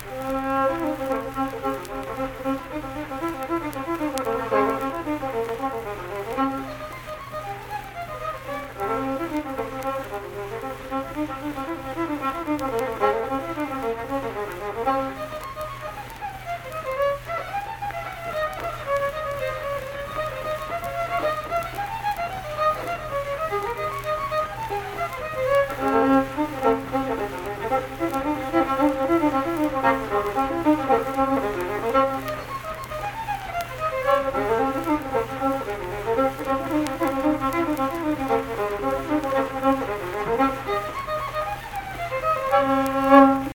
Unaccompanied fiddle music
Instrumental Music
Fiddle
Saint Marys (W. Va.), Pleasants County (W. Va.)